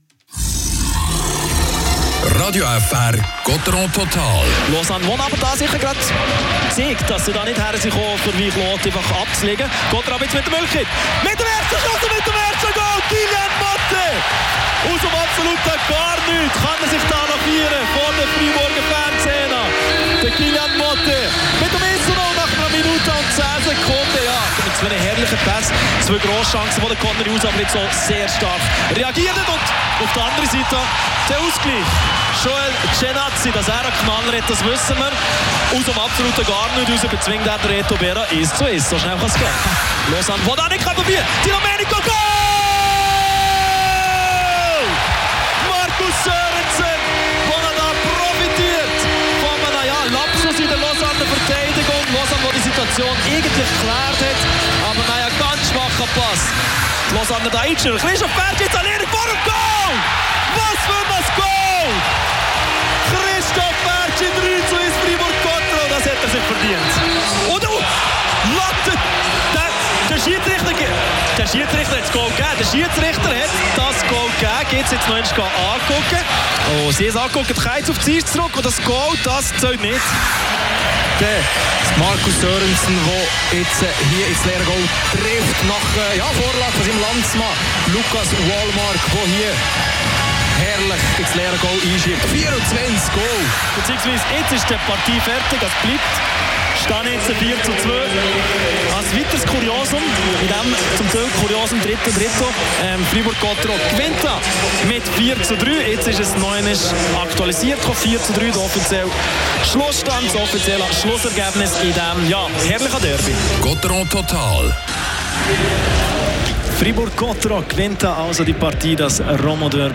Das war eine grosse Leistung: Gottéron siegt gegen Lausanne mit 4-3. Nach der Partie erscheint ein munterer Reto Berra zum Interview.